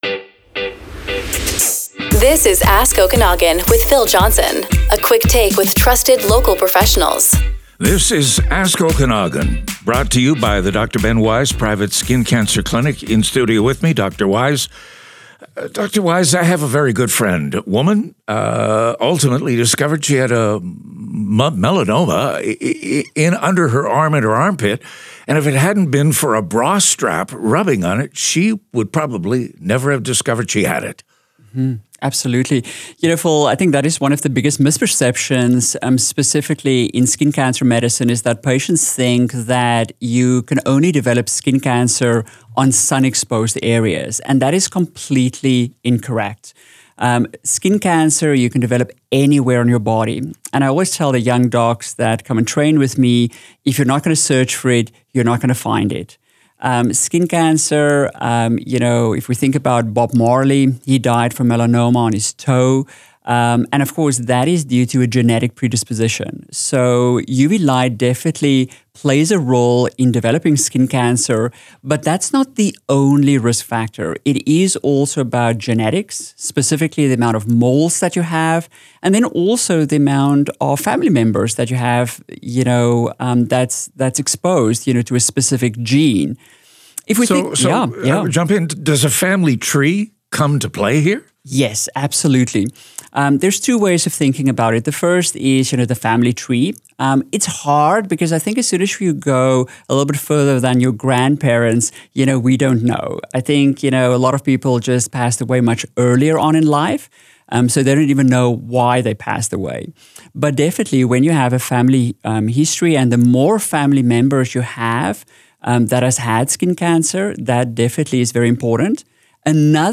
This media recording reflects a public interview and is shared for educational purposes only.